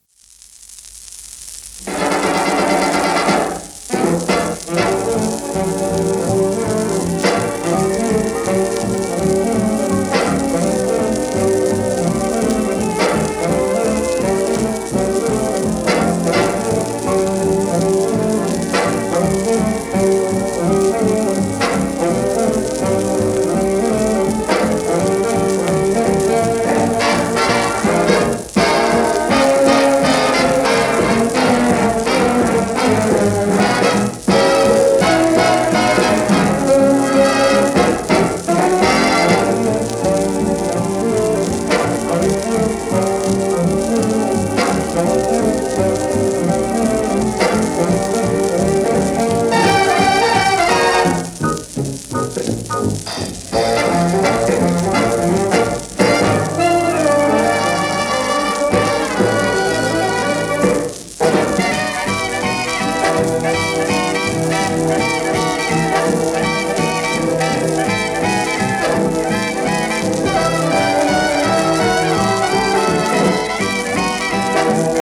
オーケストラ
盤質B+ *やや溝荒有り、B面レーベルキズ